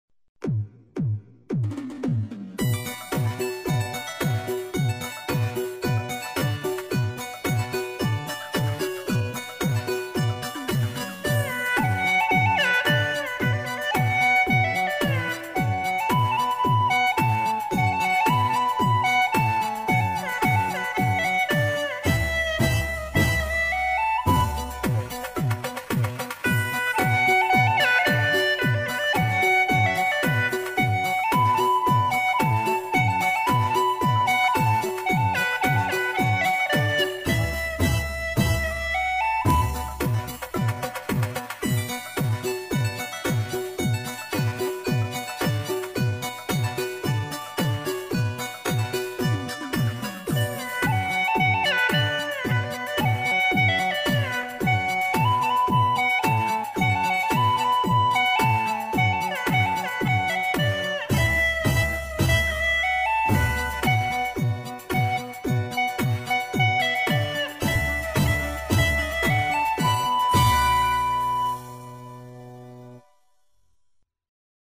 调式 : D 曲类 : 影视
葫芦丝也能演奏出俏皮搞怪的味道。